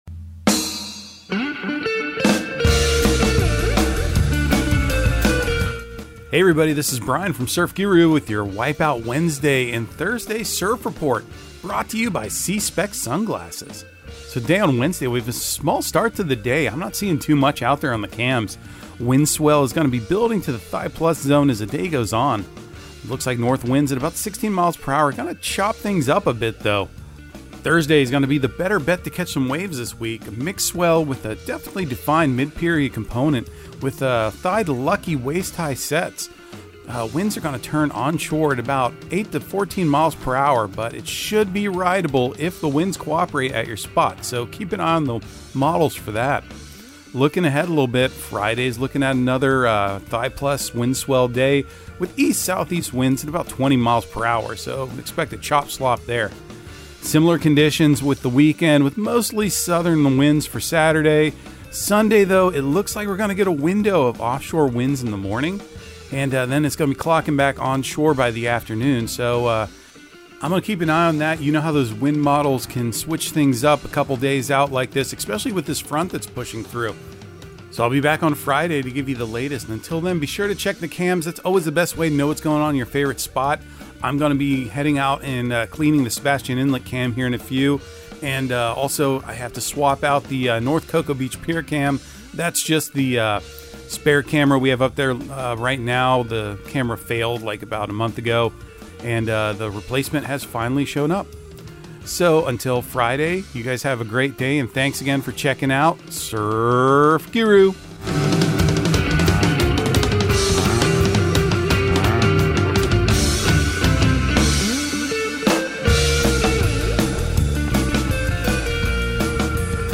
Surf Guru Surf Report and Forecast 03/29/2023 Audio surf report and surf forecast on March 29 for Central Florida and the Southeast.